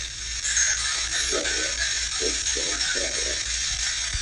Recording 5 (E.V.P.)